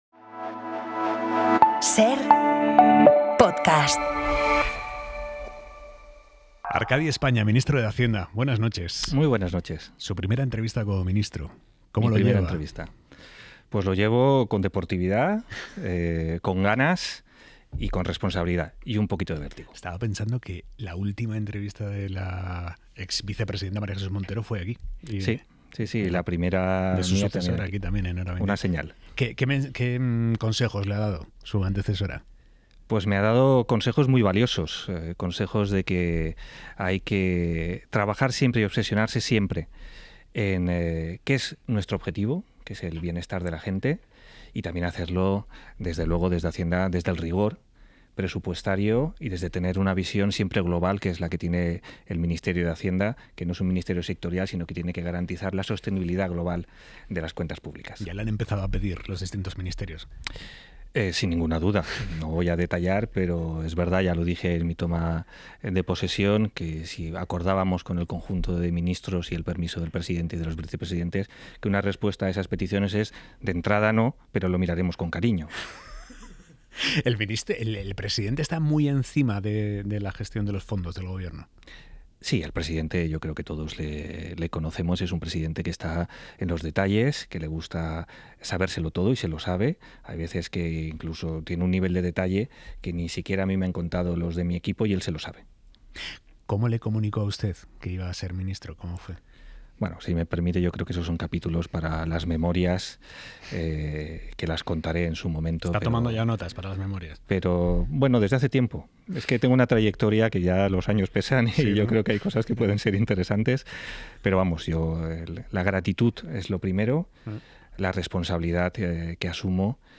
Aimar Bretos entrevista a Arcadi España. Se trata de la primera entrevista que concede España como nuevo ministro de Hacienda.